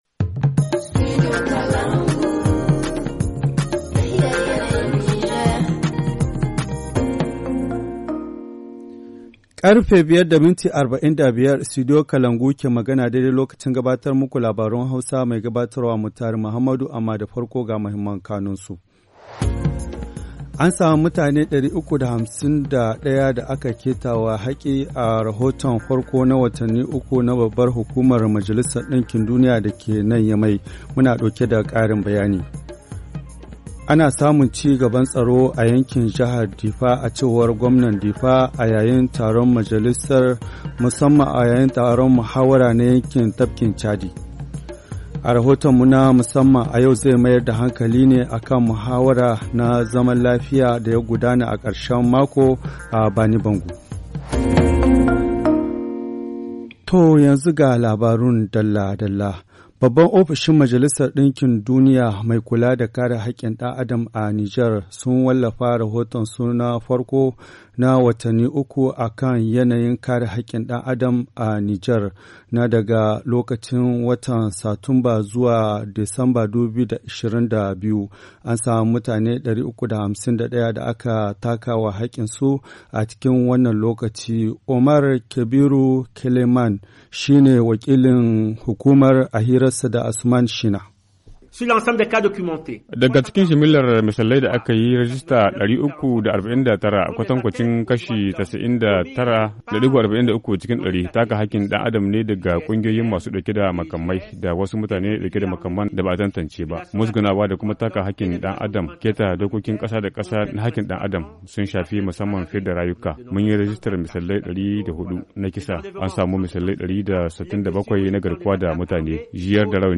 Le journal du 26 janvier 2023 - Studio Kalangou - Au rythme du Niger